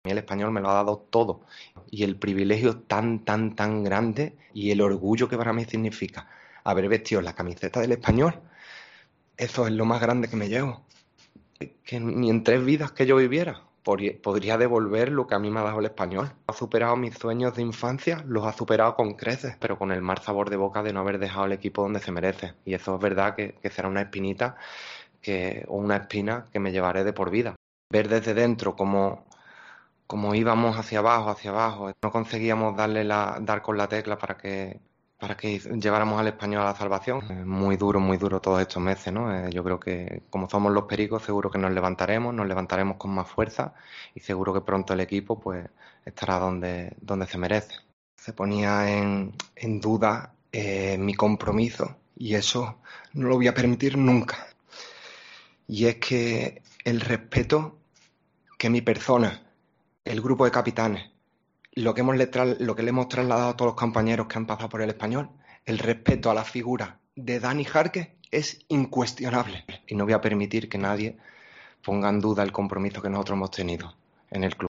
AUDIO: El capitán blanquiazul se despide en una emotiva rueda de prensa tras rescindir contrato con el club de su vida que prefiere no contar con él...